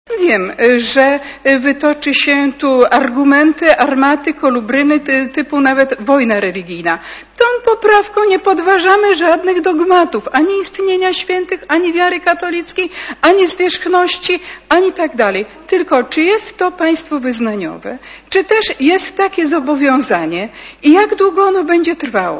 * Mówi Krystyna Sienkiewicz*